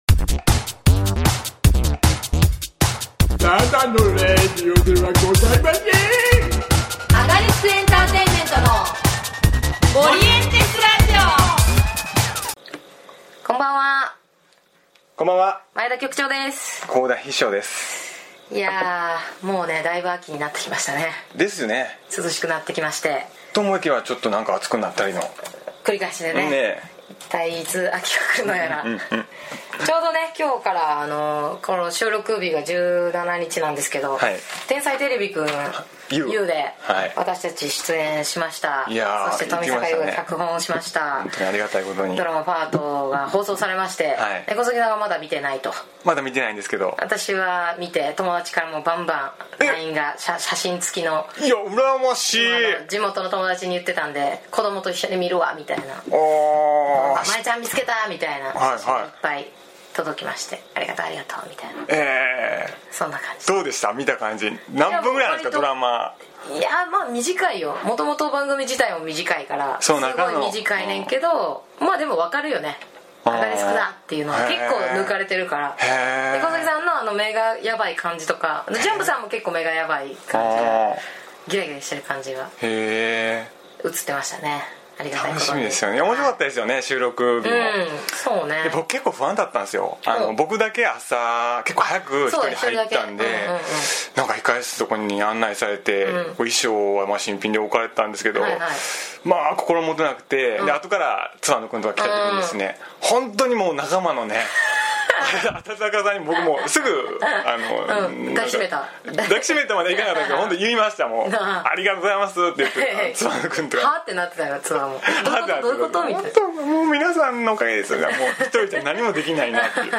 ●われこそはハガキ職人のコーナー お題は…